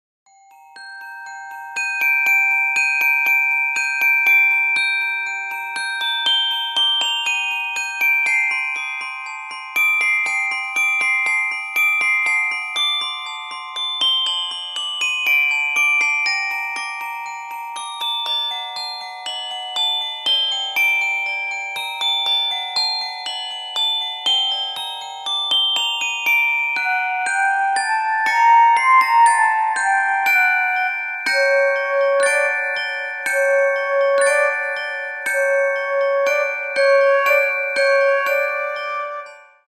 Каталог -> Для детей -> Музыкальная терапия